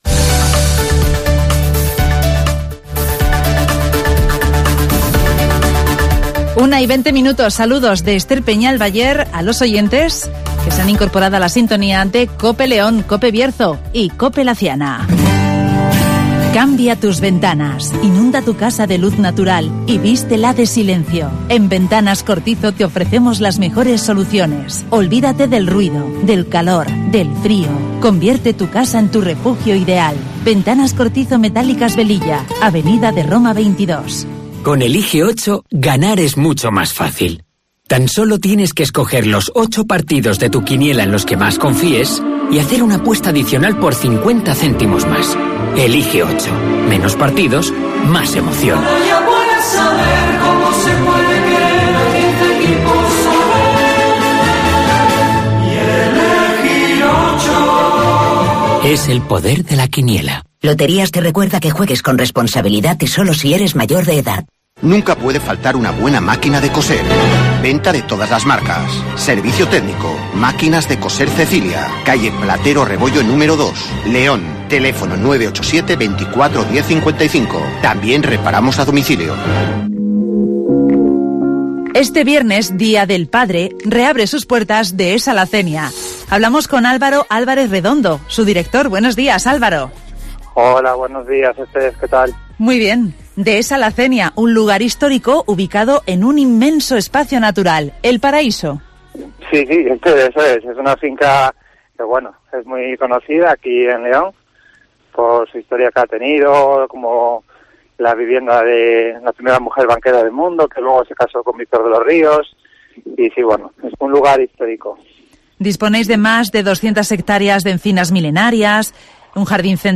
Este, viernes 19 de marzo Dehesa La Cenia reabre sus puertas (Entrevista